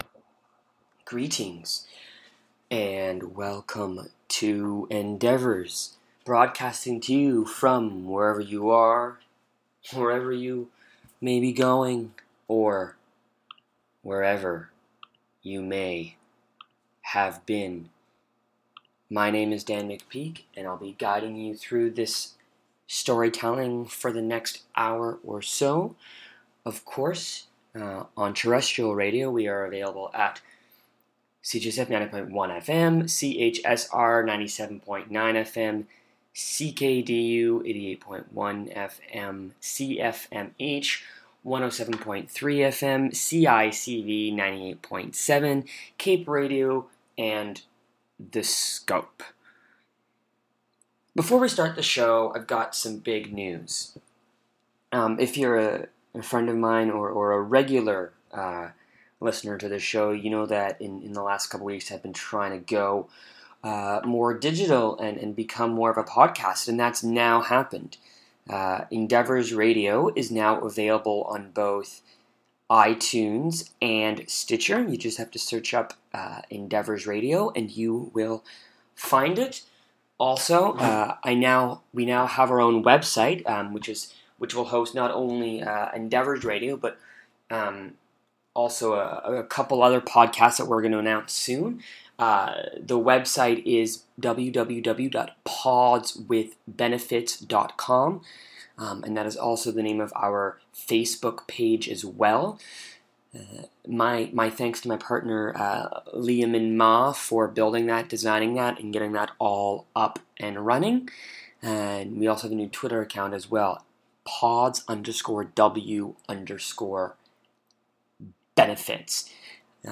Theorboist/lutenist